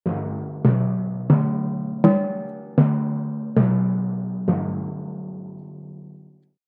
8. Так звучат литавры